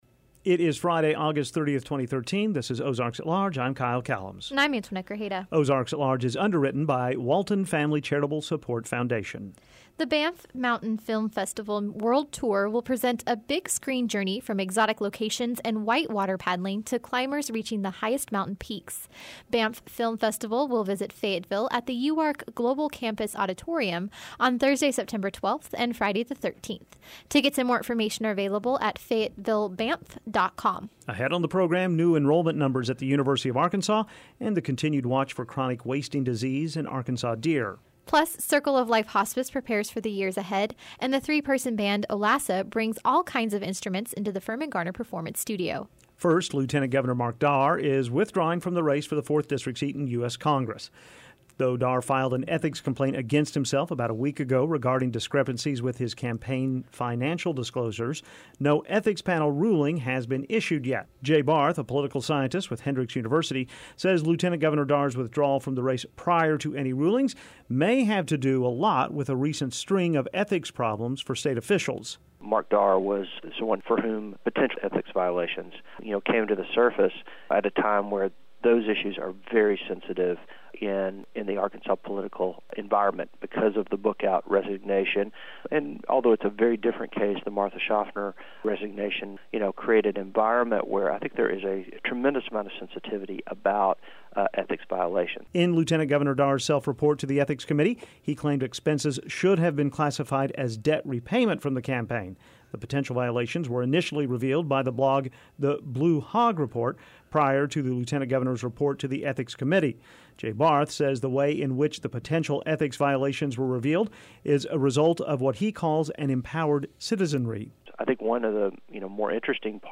We take a look at that and new hunting regulations as deer season approaches. And, the band Olassa performs inside the Firmin-Garner Performance Studio.